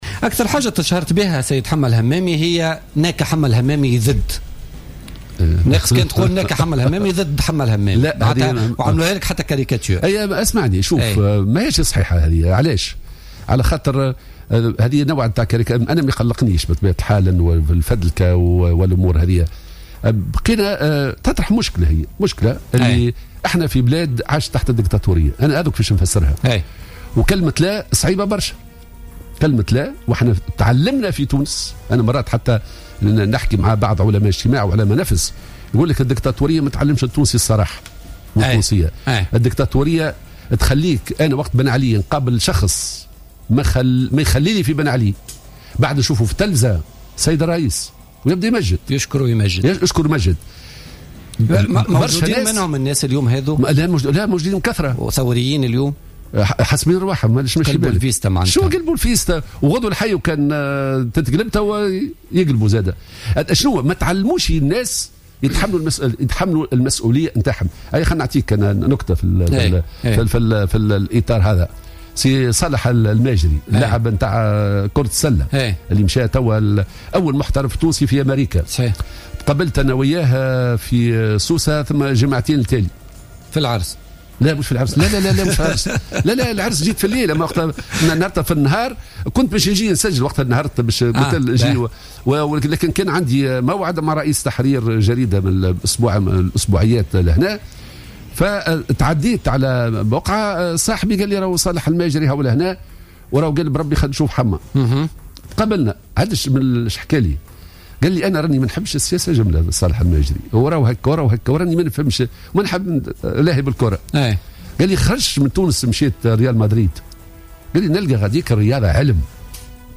أكد الأمين العام لحزب العمال والناطق الرسمي باسم الجبهة الشعبية حمة الهمامي، ضيف بوليتيكا اليوم الخميس، أن الجبهة وفي كل مرة رفضت فيه مشروعا أو قانونا تعد له السلطة إلا وقدمت بديلا عنه، والدليل على ذلك مشروع الميزانية البديل الذي قدمته لحكومة المهدي جمعة، كما تستعد حاليا لتقديم مشروع قانون جديد بديل لقانون المصالحة الاقتصادية والمالية.